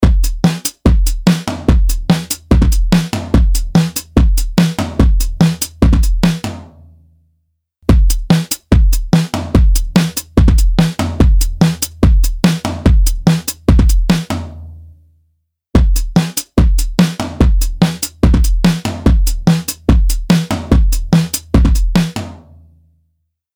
H910 Harmonizer | Drum Loop | Preset: Drum Thickener 2 (Dual)
H910-Dual-Harmonizer-Eventide-Drum-Loop-Drum-Thickener-2.mp3